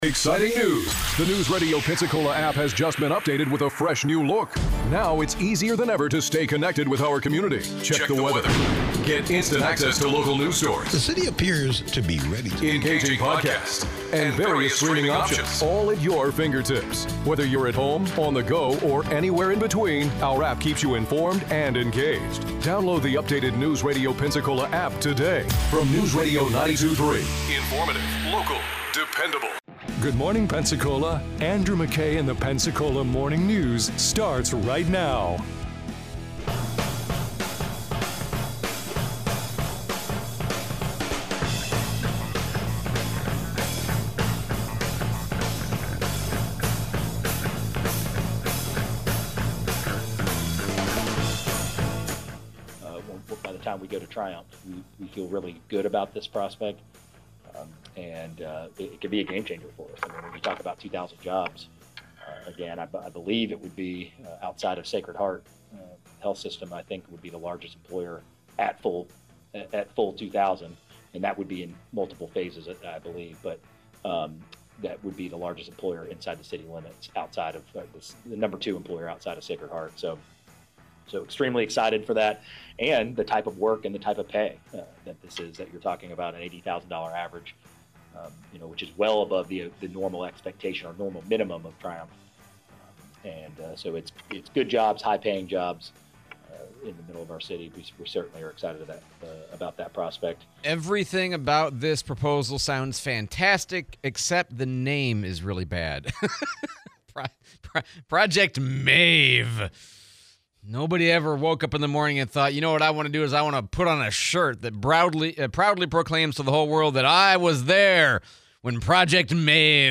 Economic development project in P'cola, Sheriff Simmons interview